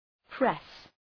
Προφορά
{pres}